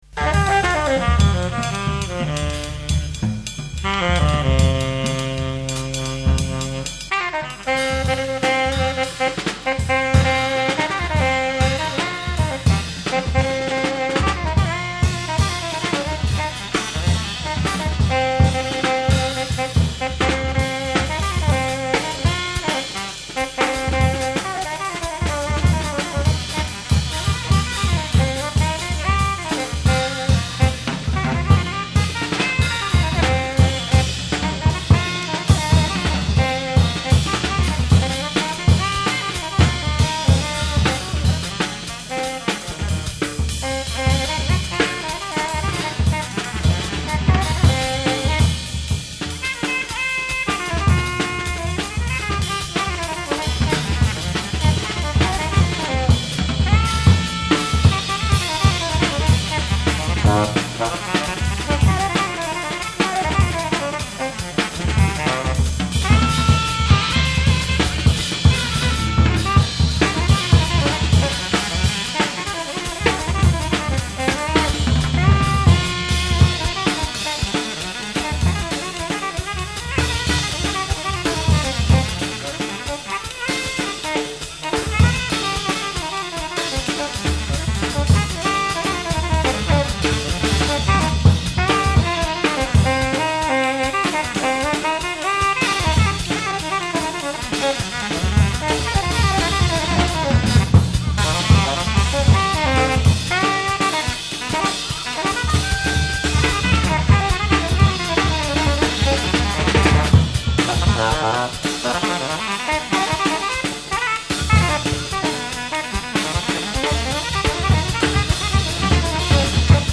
Recorded live at Willisau, Switzerland